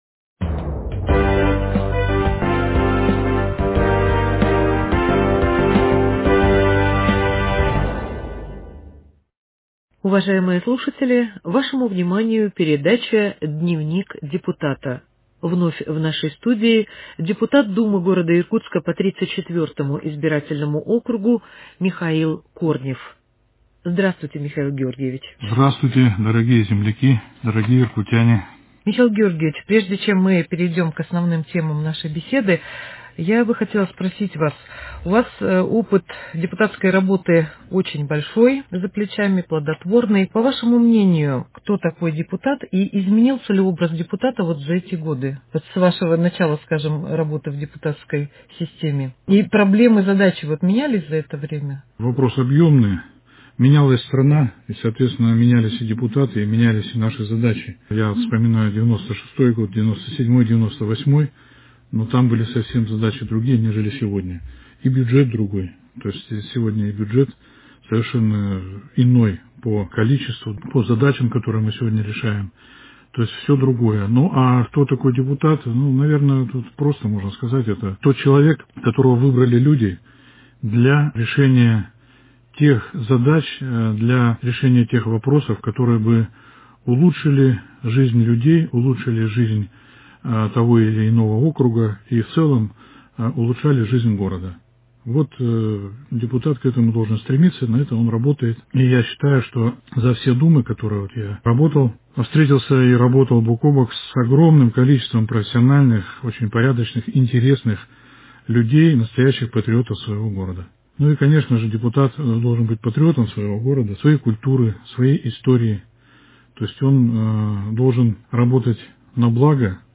С депутатом Думы Иркутска по 34-му избирательному округу Михаилом Корневым